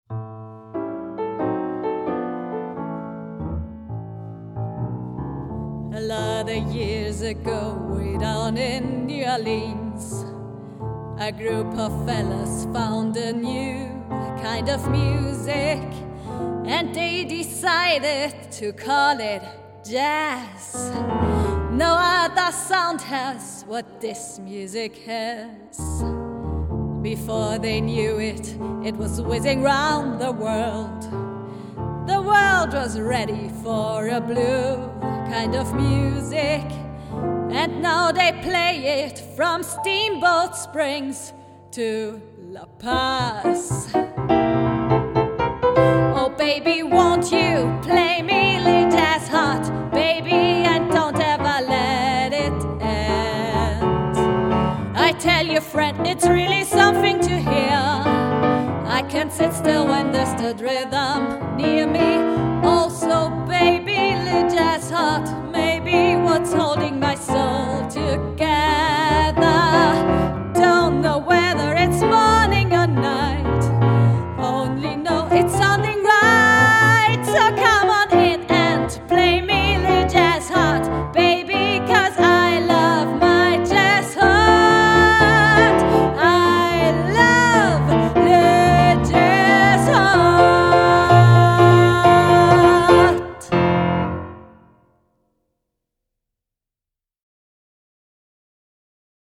Gesangsbeispiel das Lied
Gesang Beispiel: